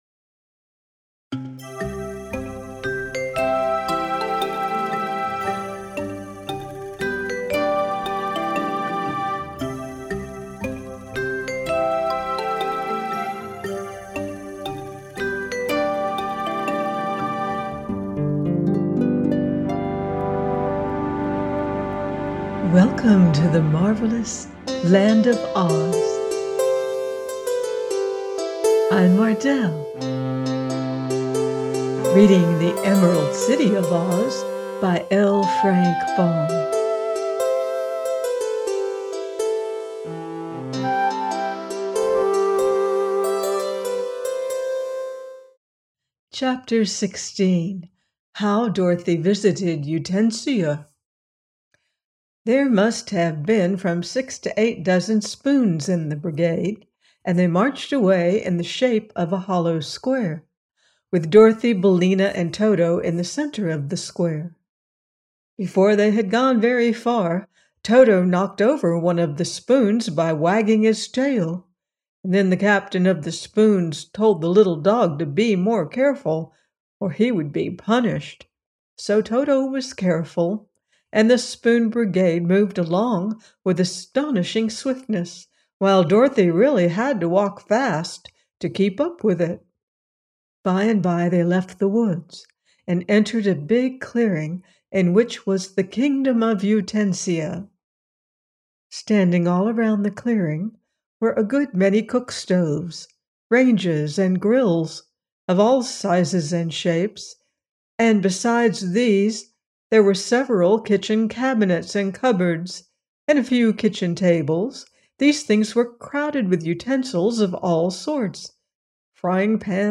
The Emerald City Of OZ – by L. Frank Baum - audiobook